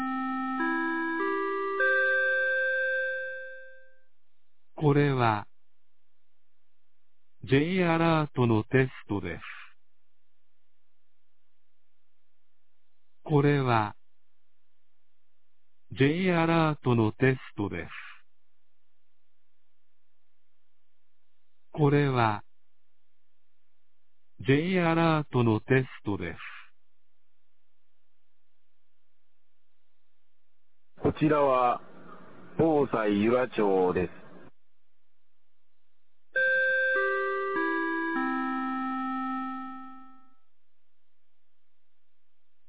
2025年08月20日 11時01分に、由良町から全地区へ放送がありました。